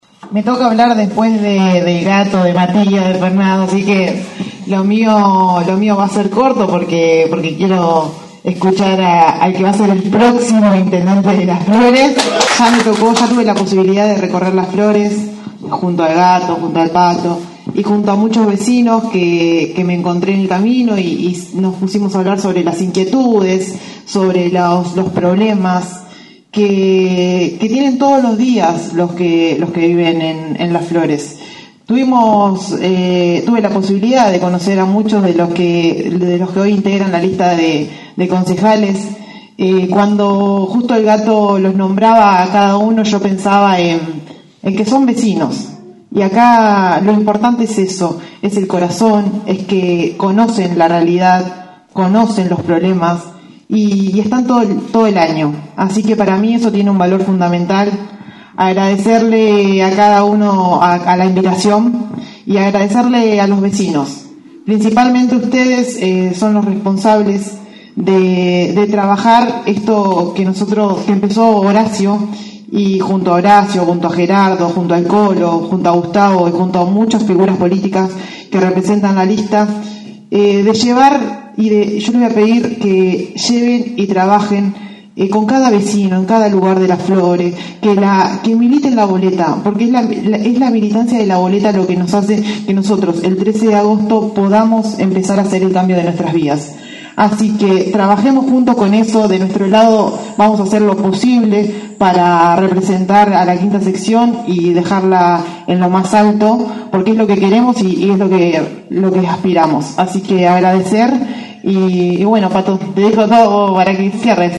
Con la presencia de distintos dirigentes y pre candidatos de la 5ta sección electoral, se inauguró el bunker electoral en la ex panadería Chateláin en calle San Martin al 146.